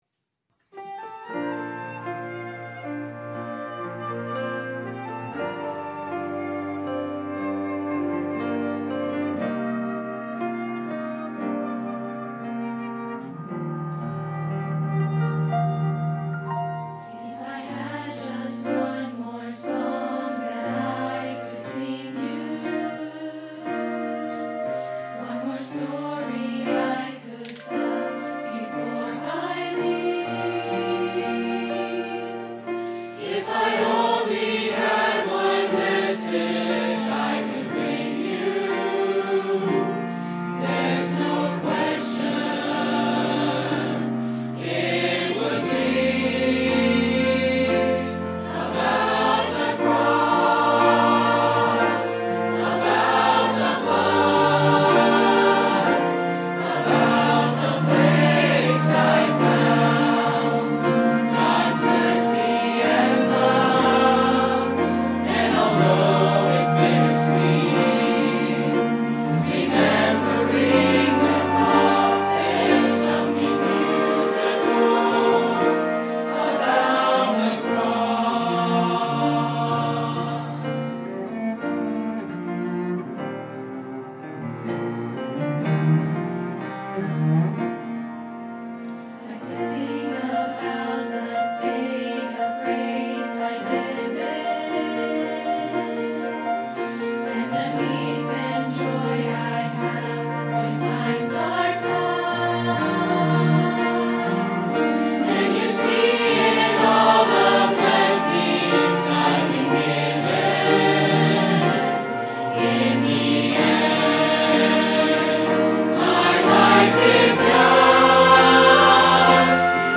by VBC Choir & Orchestra | Verity Baptist Church
About-the-Cross-VBC-Choir-amp-Orchestra-Easter-2023.mp3